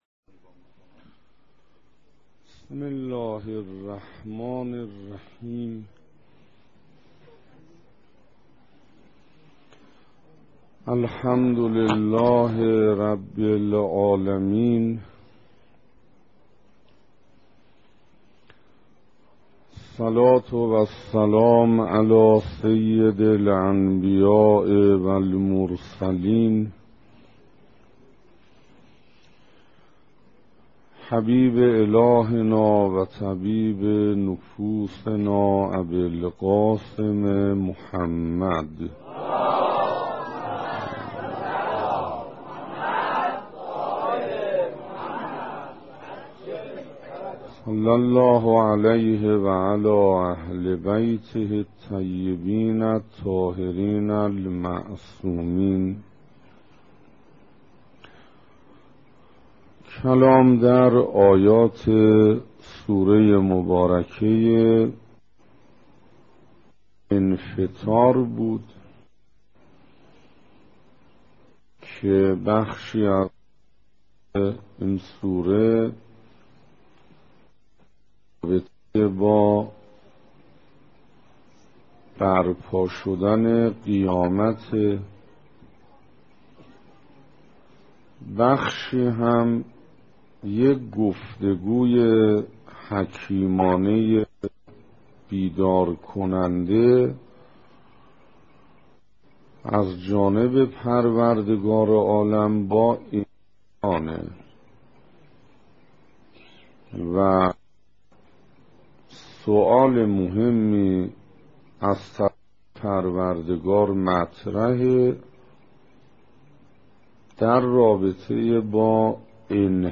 سخنراني سوم
صفحه اصلی فهرست سخنرانی ها تفسير سوره انفطار سخنراني سوم (تهران حسینیه حضرت علی اکبر (ع)) جمادی الثانی1428 ه.ق - خرداد1386 ه.ش دانلود متاسفم..